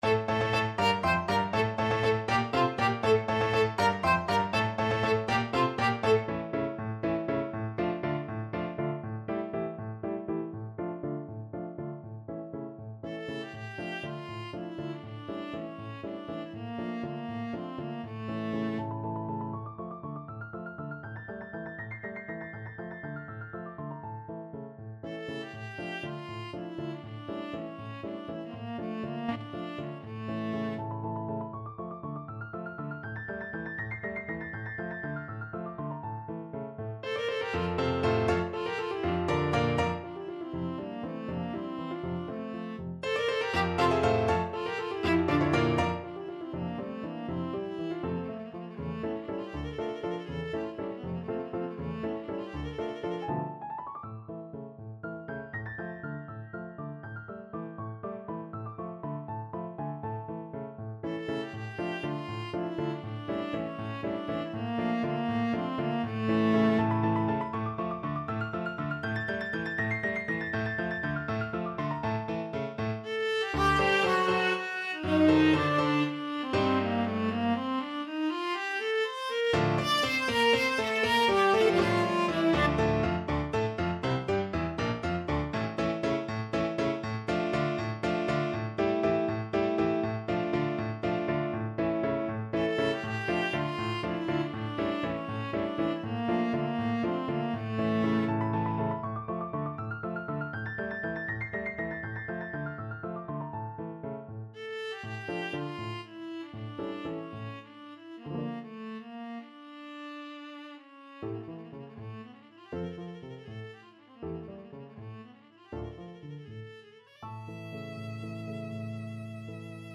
Viola
3/8 (View more 3/8 Music)
Ab4-E6
F major (Sounding Pitch) (View more F major Music for Viola )
Allegro vivo (.=80) (View more music marked Allegro)
Classical (View more Classical Viola Music)
bizet_aragonaise_VLA.mp3